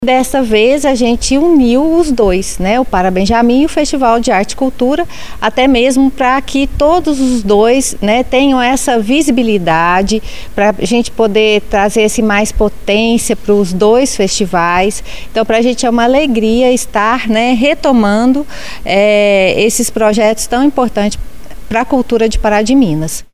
Clique e ouça Isabel Faria